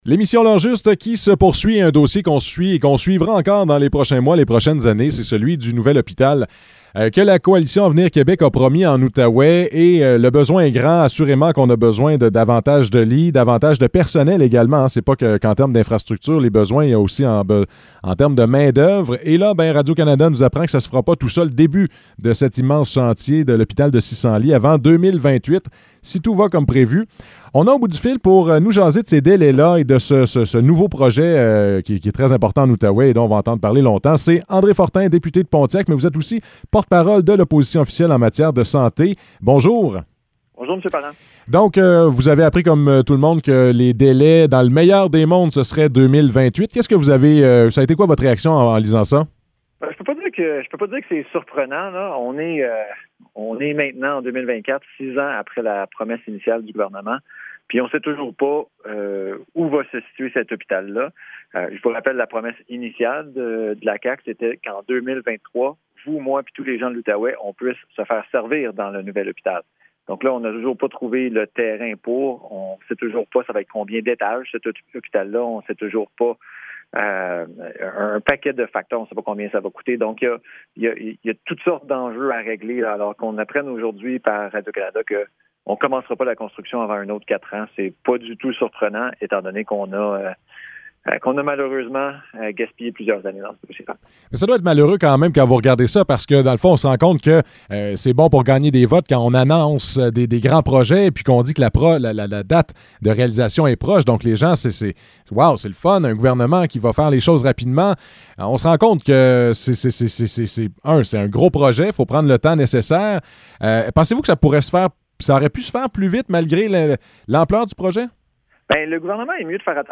Entrevue avec André Fortin, député de Pontiac | CHGA